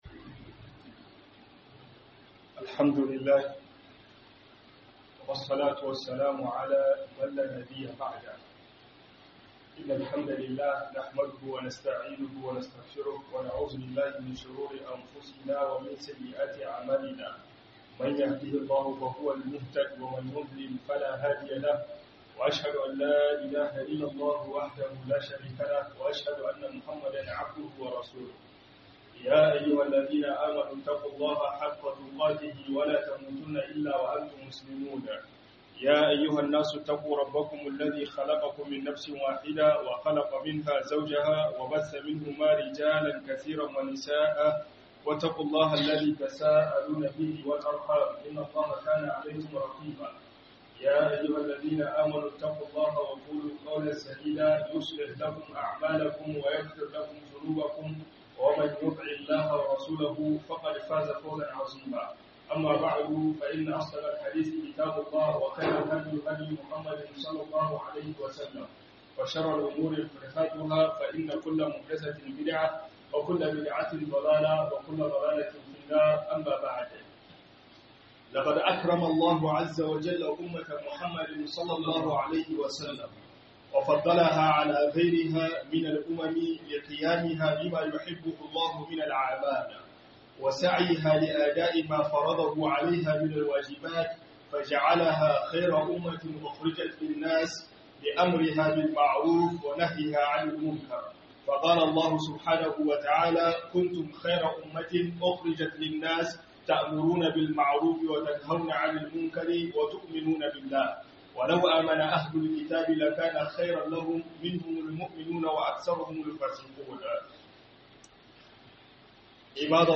Umarni da kekawa - HUDUBA